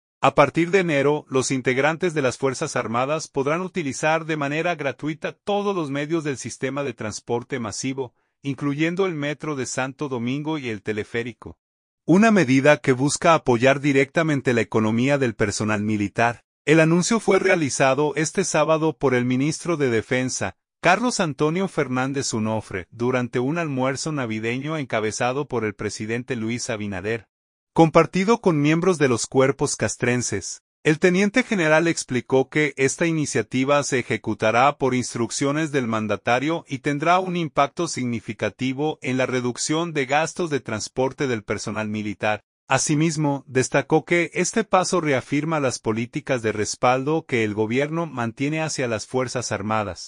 El anuncio fue realizado este sábado por el ministro de Defensa, Carlos Antonio Fernández Onofre, durante un almuerzo navideño encabezado por el presidente Luis Abinader, compartido con miembros de los cuerpos castrenses.